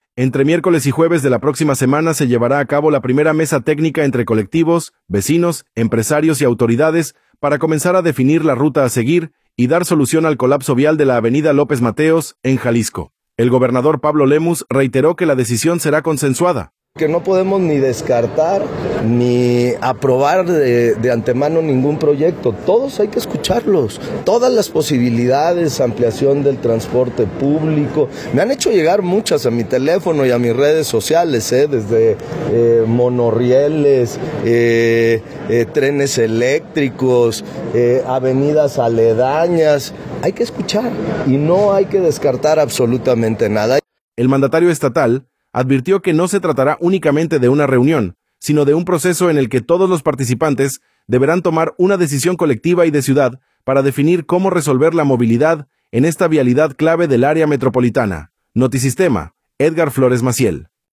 audio Entre miércoles y jueves de la próxima semana se llevará a cabo la primera mesa técnica entre colectivos, vecinos, empresarios y autoridades para comenzar a definir la ruta a seguir y dar solución al colapso vial de la avenida López Mateos, en Jalisco. El gobernador Pablo Lemus reiteró que la decisión será consensuada.